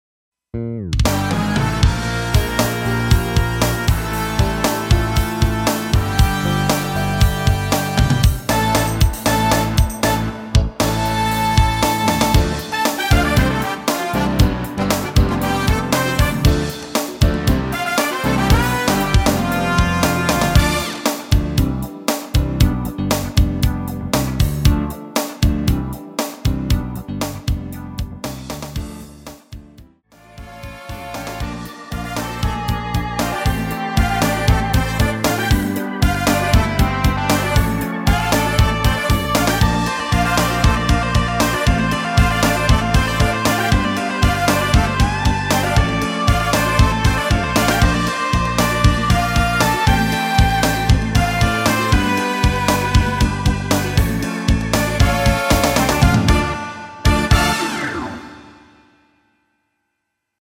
엔딩이 페이드 아웃이라 엔딩 만들었습니다.
Dm
앞부분30초, 뒷부분30초씩 편집해서 올려 드리고 있습니다.